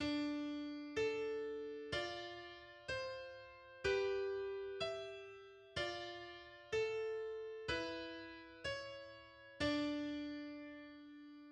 Due note contro una
Un esempio di contrappunto di seconda specie (